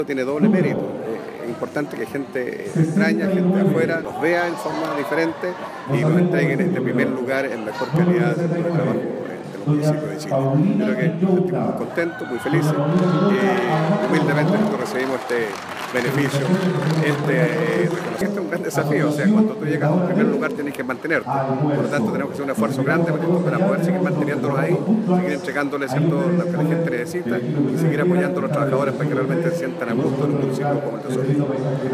El alcalde de Osorno, recibió en Santiago la distinción desde el mismo lugar se refirió con orgullo al reconocimiento.